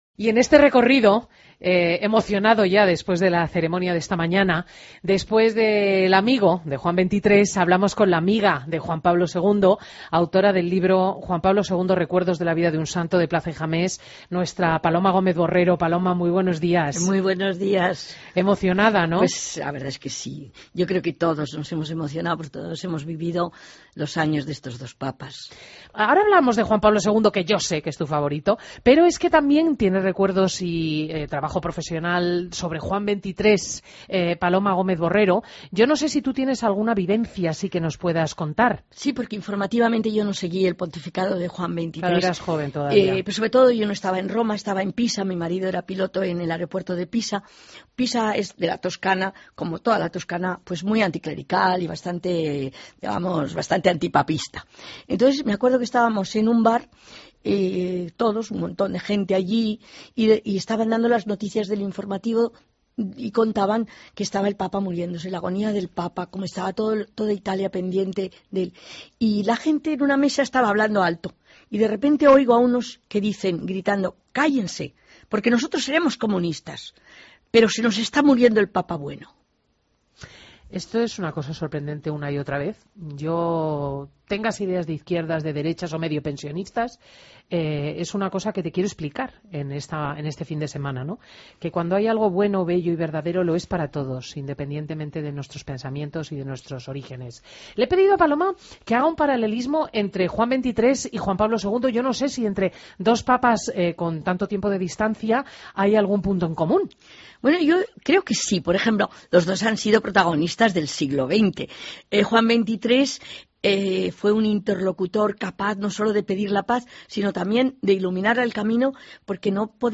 Entrevista a Paloma Gómez Borrero en Fin de Semana COPE